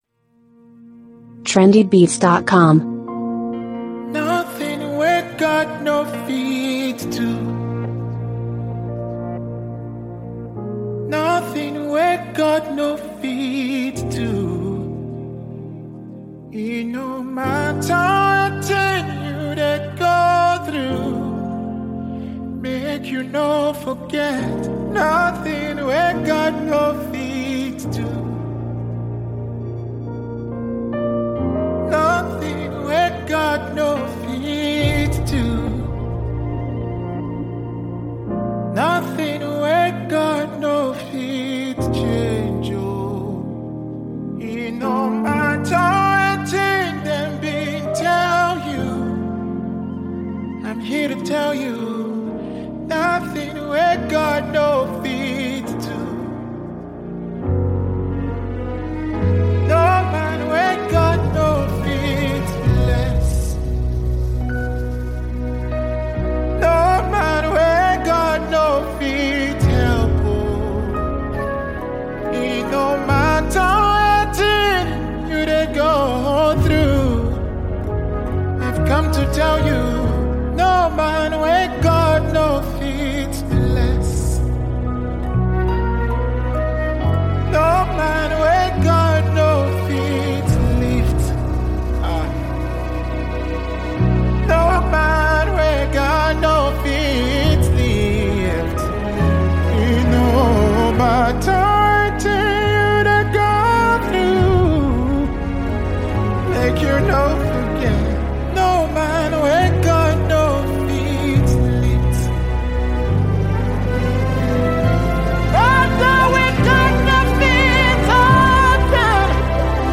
Live Ministration